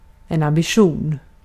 Ääntäminen
IPA : /æmˈbɪ.ʃən/